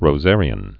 (rō-zârē-ən)